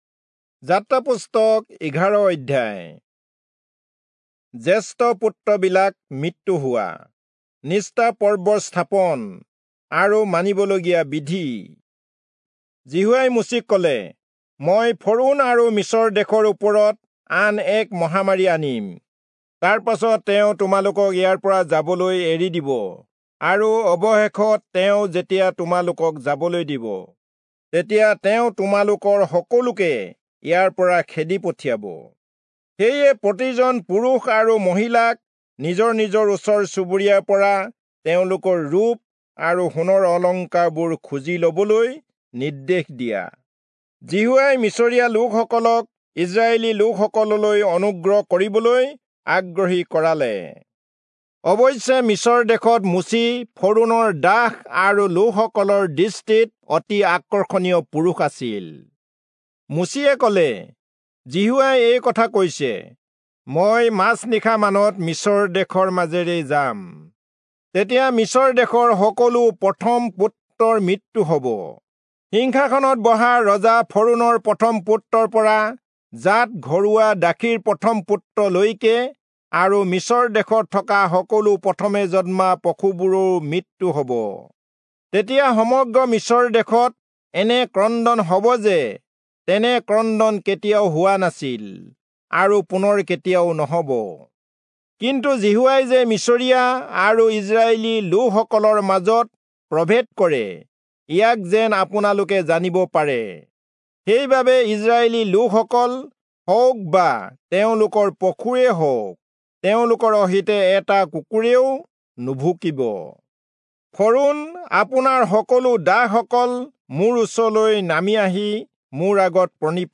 Assamese Audio Bible - Exodus 30 in Kjv bible version